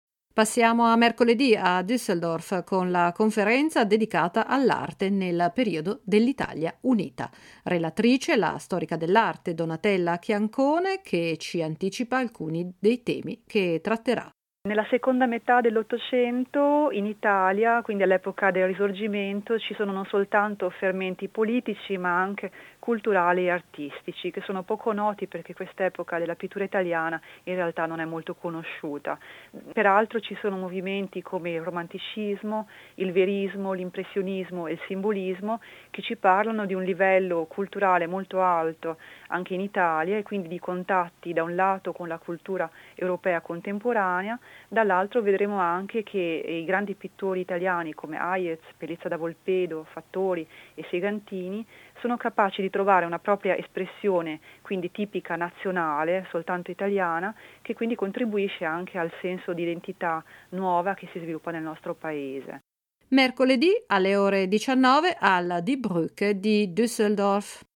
conferenza, 15.2.2012